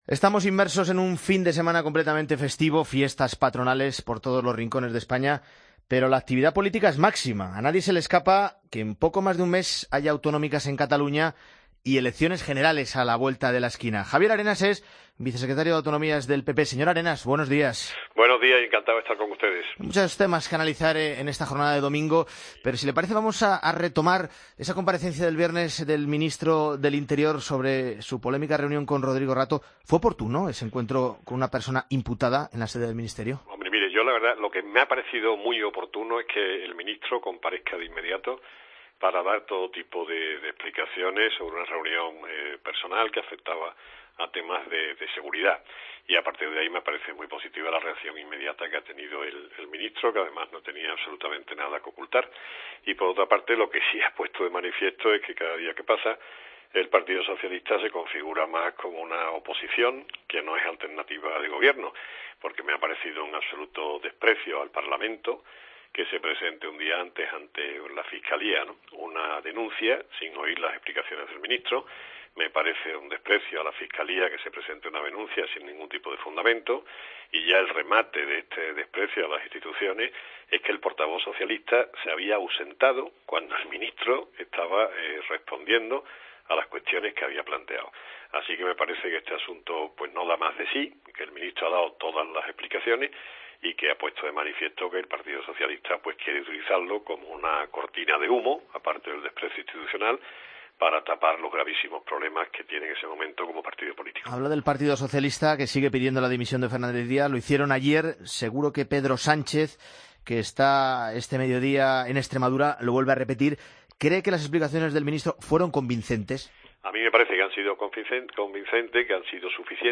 AUDIO: Escucha la entrevista a Javier Arenas en La Mañana de Fin de Semana